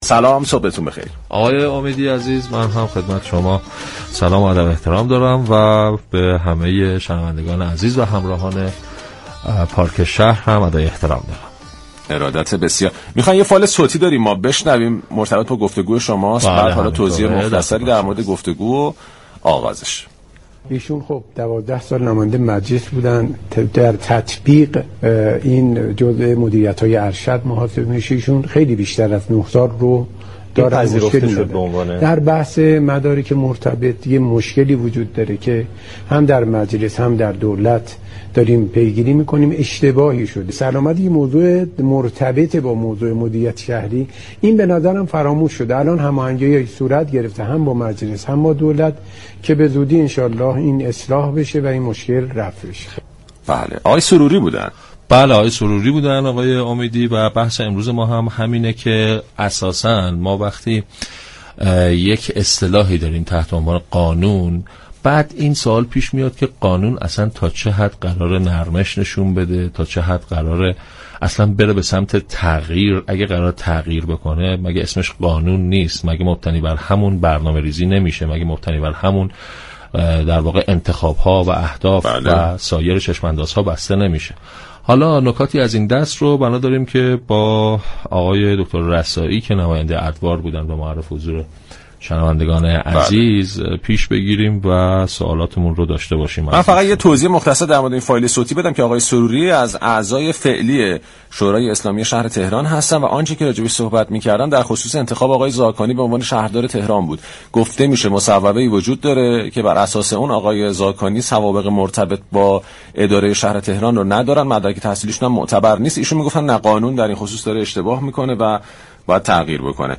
به گزارش پایگاه اطلاع رسانی رادیو تهران، حجت الاسلام حمید رسایی نماینده مجلس در دوره های گذشته مجلس شورای اسلامی در گفتگو با برنامه‌ی پارك شهر رادیو تهران درخصوص اخبار منتشر شده از مدرك تحصیلی غیرمرتبط علیرضا زاكانی شهردار منتخب تهران گفت: این اشكالی كه مطرح شده به معنای این نیست كه به ظرفیت‌ها و توانایی‌های علیرضا زاكانی اعتقاد نداشته و فرد انتخاب شده را قبول ندارند.